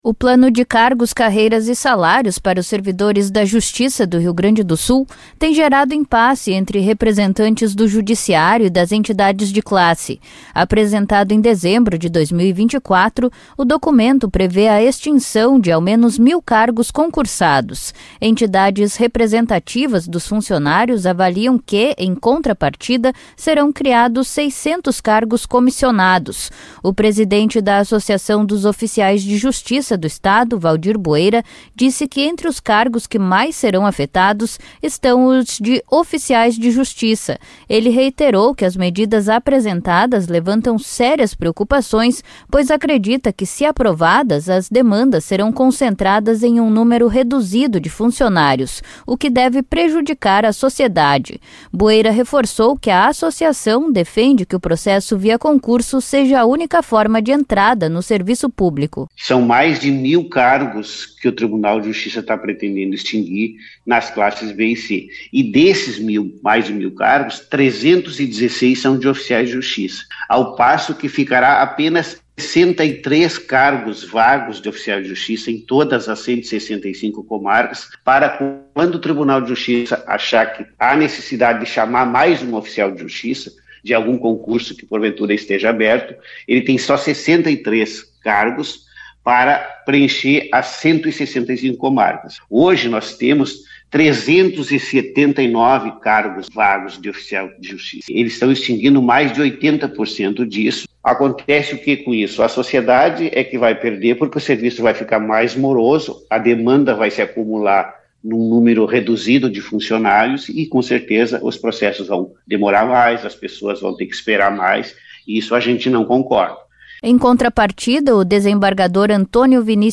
concedeu entrevista à Rádio Guaíba sobre a intenção do TJRS de extinguir mais de mil cargos destinados à seleção via concurso público